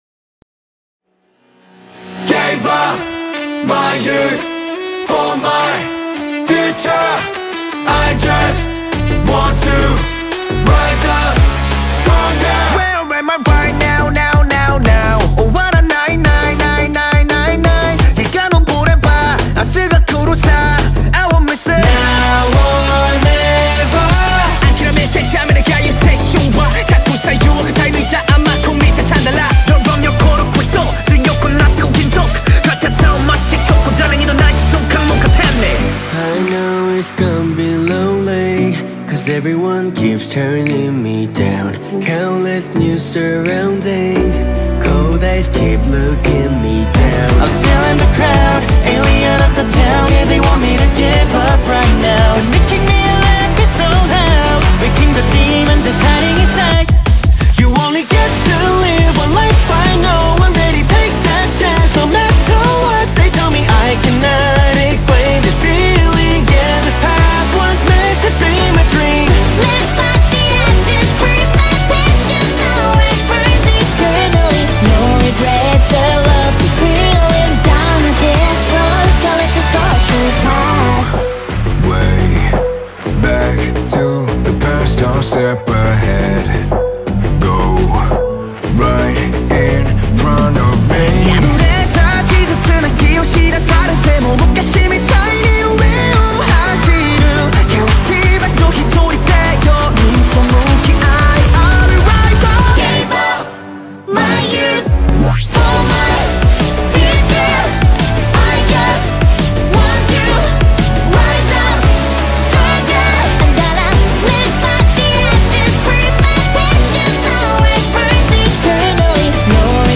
BPM86-172
Audio QualityPerfect (High Quality)